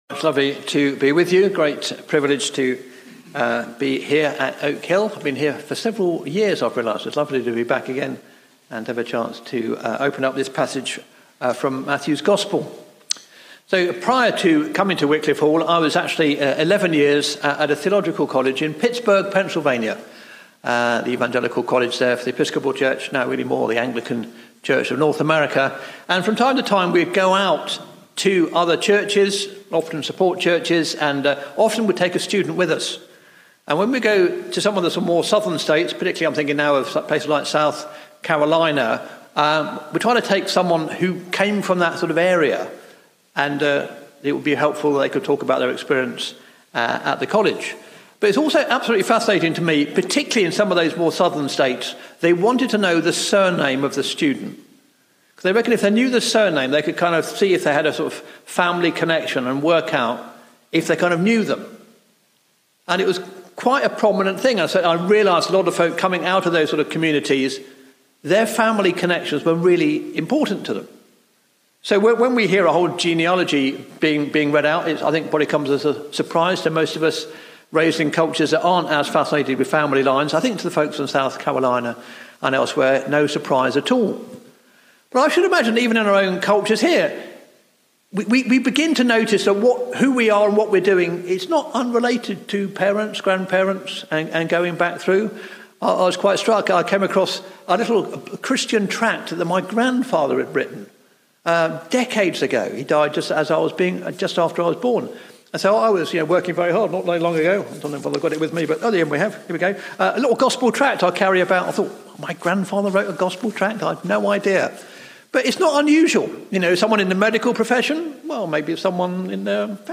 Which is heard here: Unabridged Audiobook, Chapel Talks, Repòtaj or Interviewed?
Chapel Talks